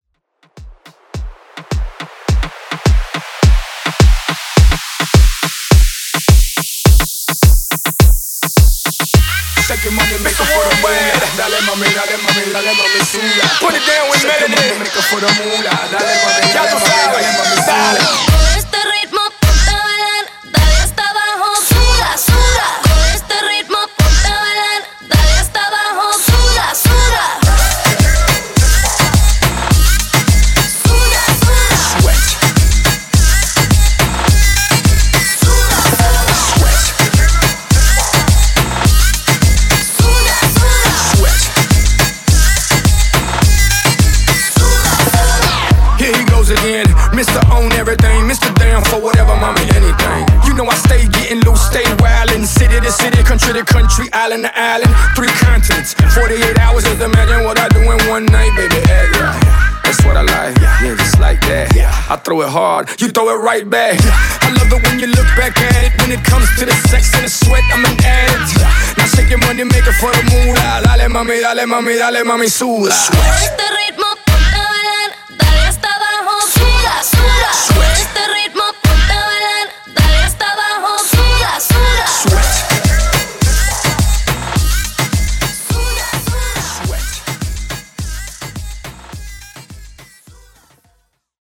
Dance Mixshow)Date Added